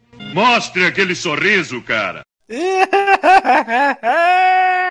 hahaha_EEk8Dbx.mp3